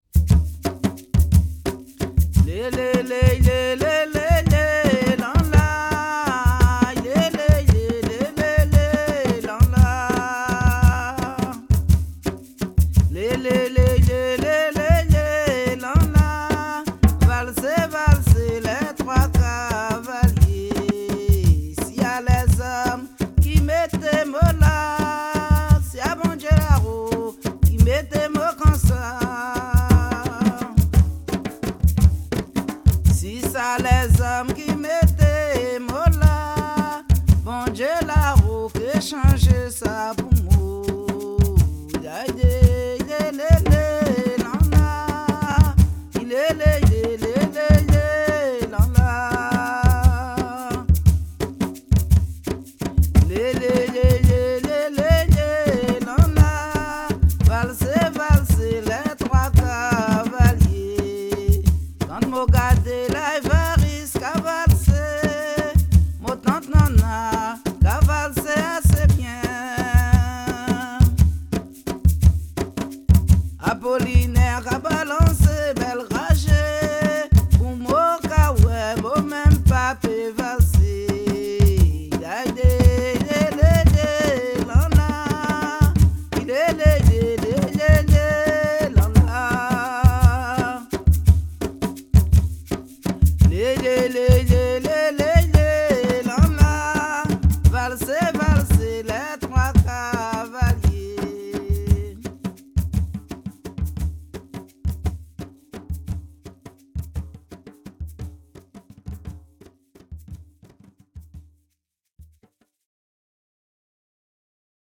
danse : grajévals (créole)
Pièce musicale inédite